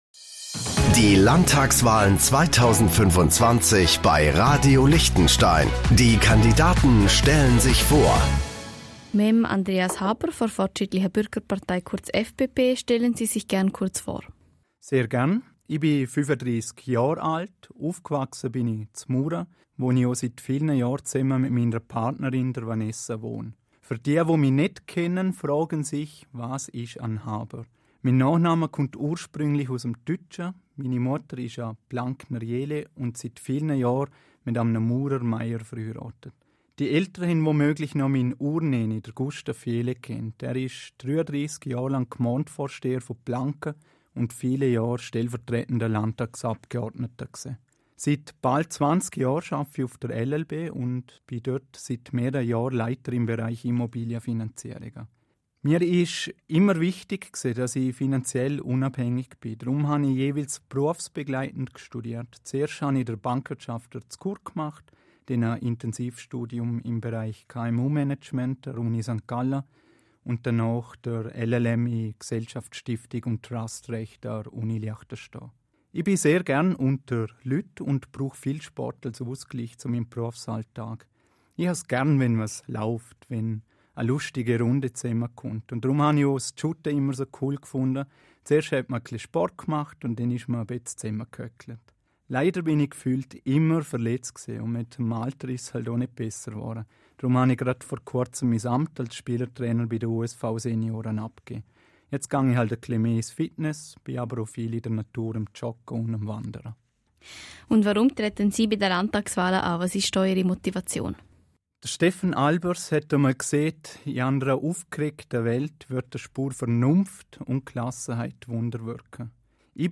Landtagskandidat